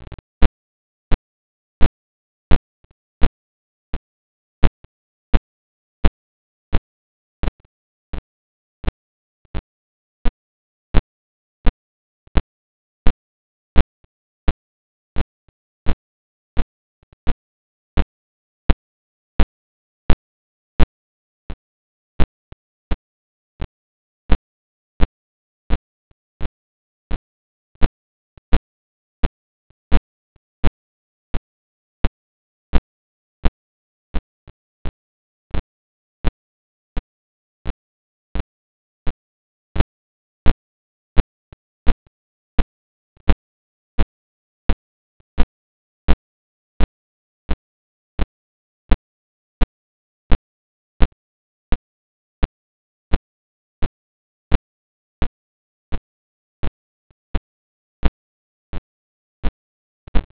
pulsar sound